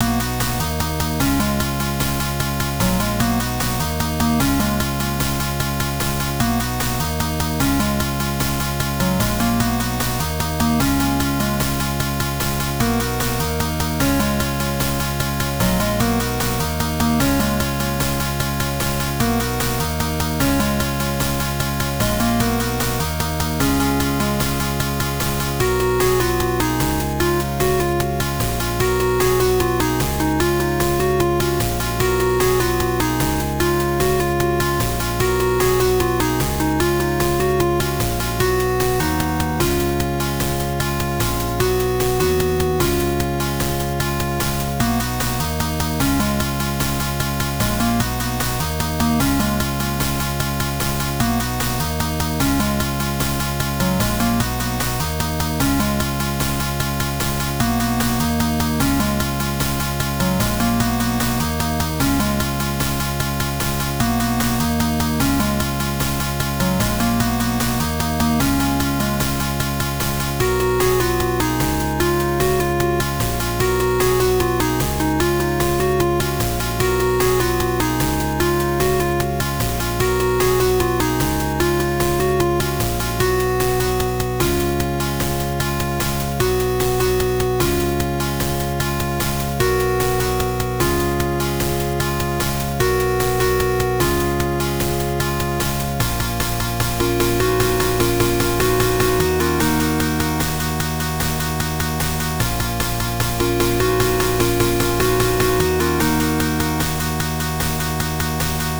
goofy kitters song (ESFM version)